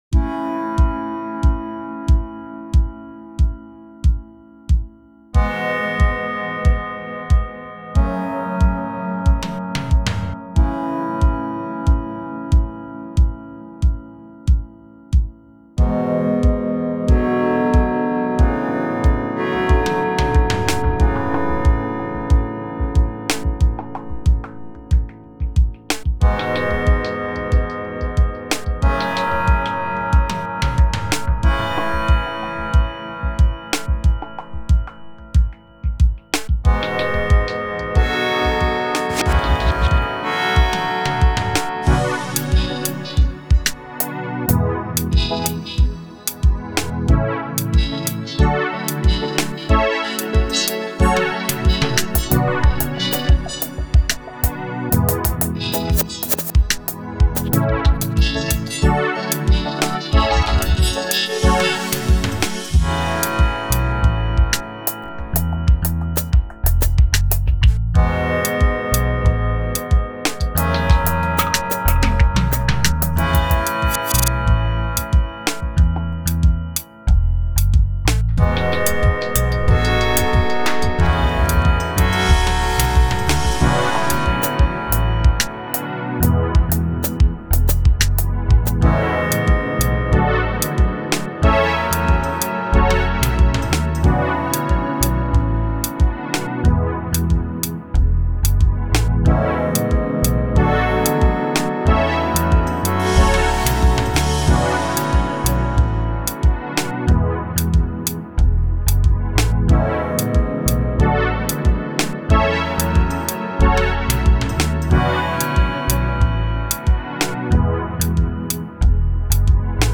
Genre Latin